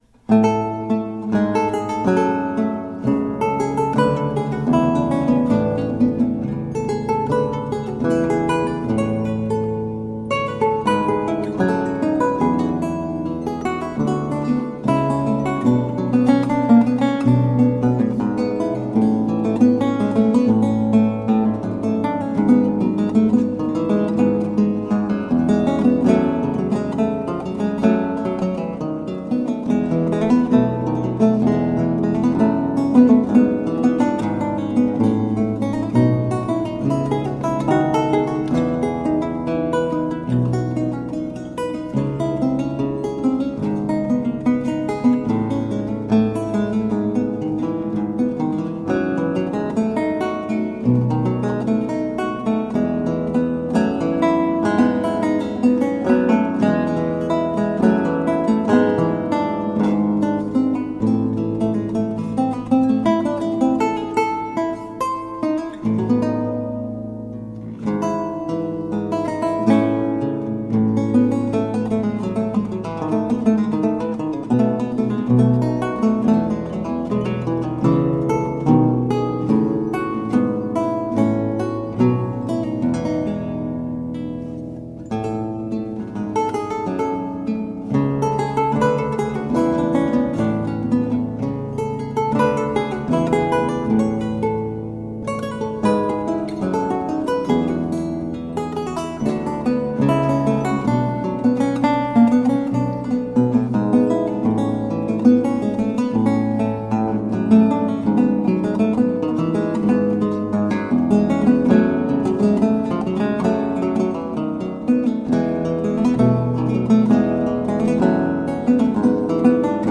A marvelous classical spiral of lute sounds.